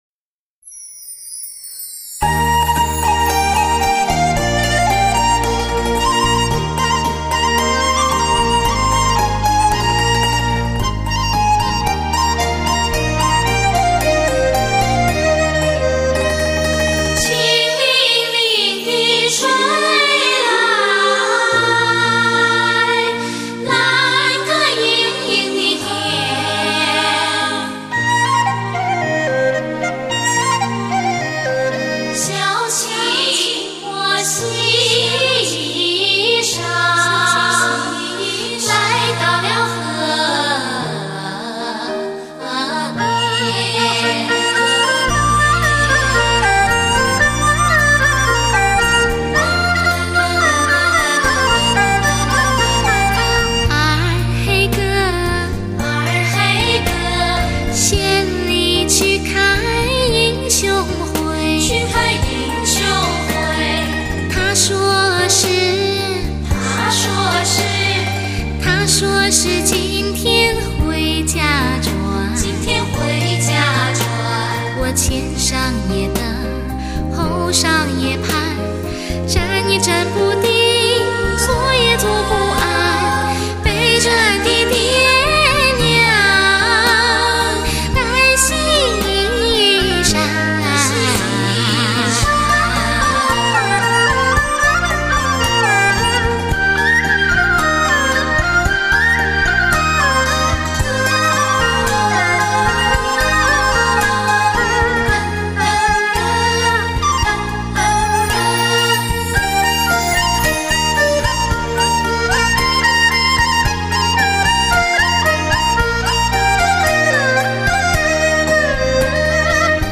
类型: 天籁人声
明快而富青春气息的伴奏，清澈飘逸的女声带你走进一个山清水秀、景色宜人的山乡之中。